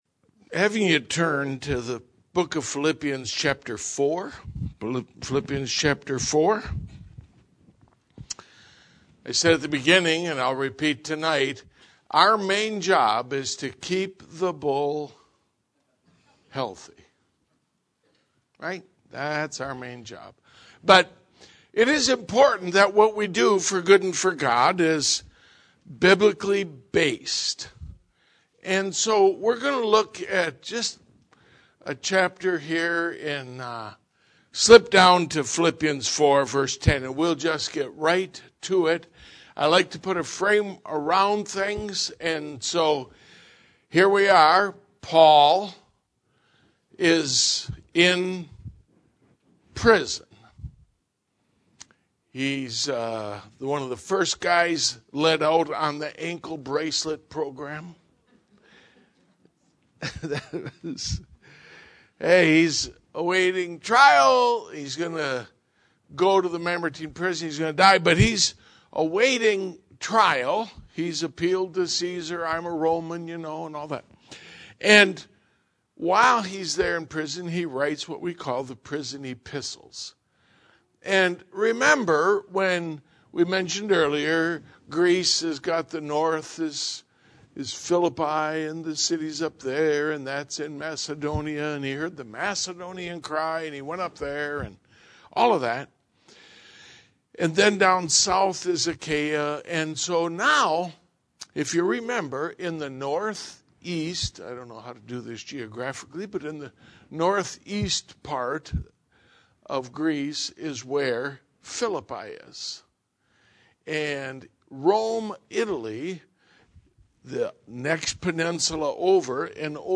This sermon covers excerpts from all four chapters of Philippians and explains why Epaphroditus had nearly worked himself to death supporting Paul in the ministry.